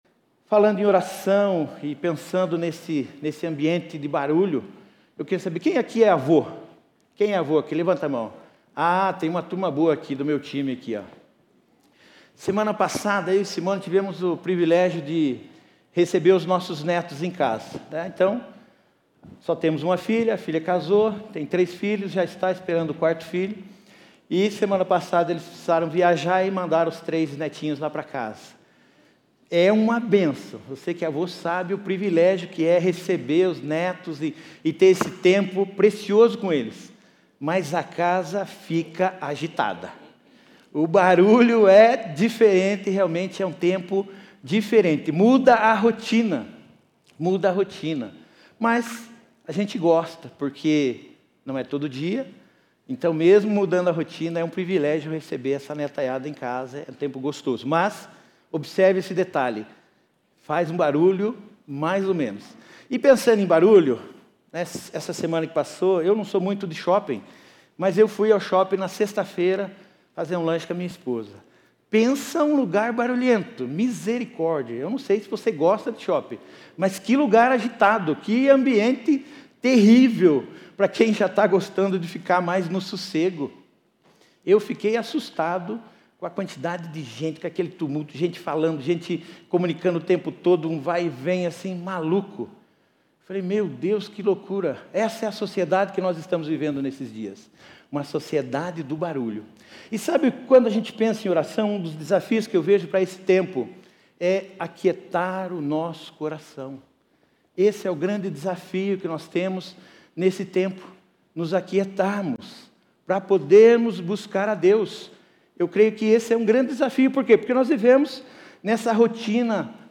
Mensagem
na Igreja Batista do Bacacheri